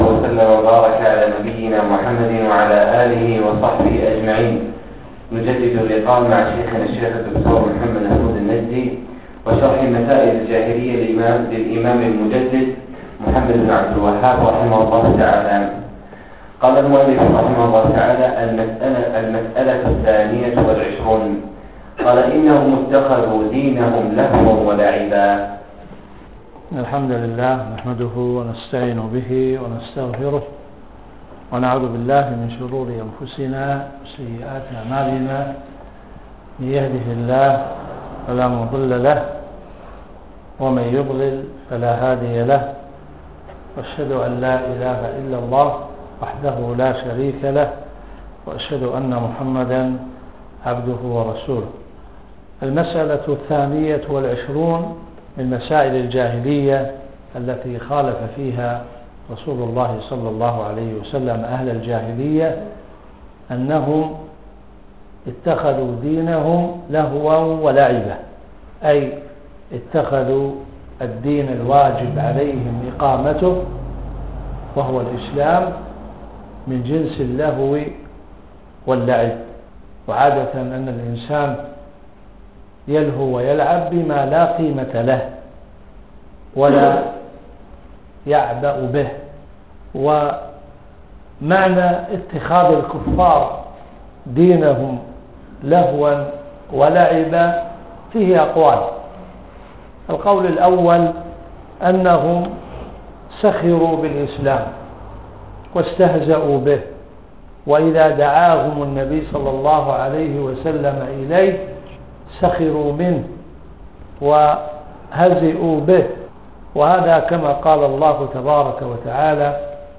محاضرات ودروس